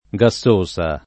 gassosa [ g a SS1S a ]